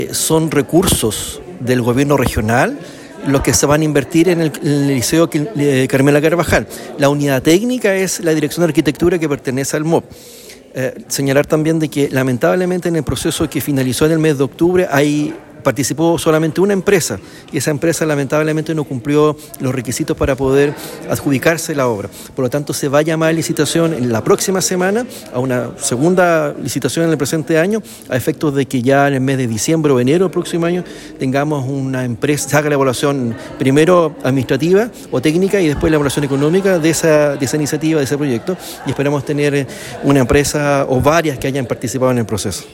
Según informó el Seremi de Obras Públicas de la Región de Los Lagos, Juan Alvarado, ninguna de las empresas que participaron en el proceso cumplió con los requisitos necesarios para adjudicarse el proyecto.